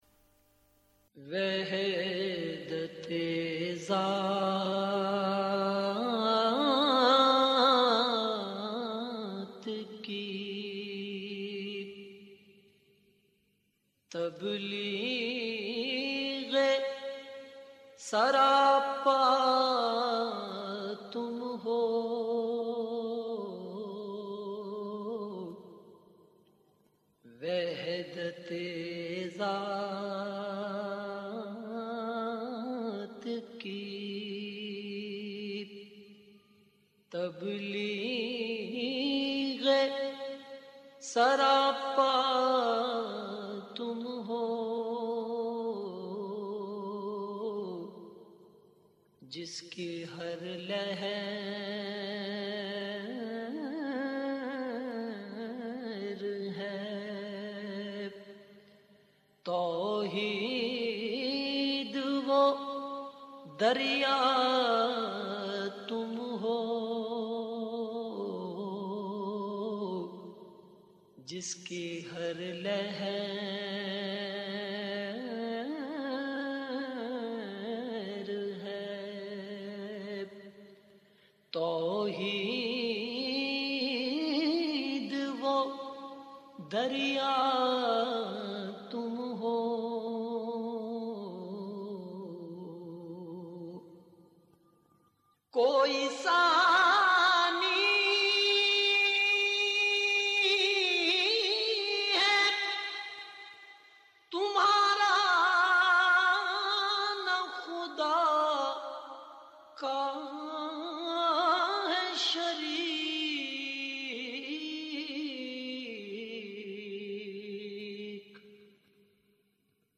نعت رسول مقبول صلّٰی اللہ علیہ وآلہ وسلم